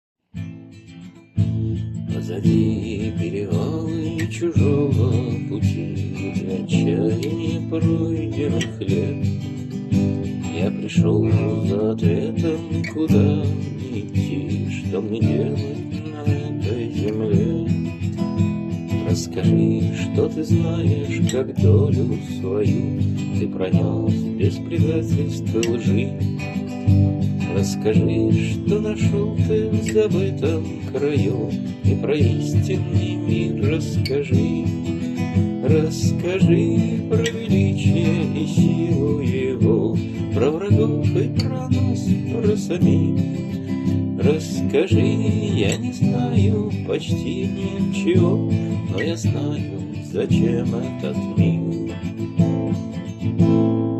miroslav.mp3 (844k) Пролог. Первая ария Мирослава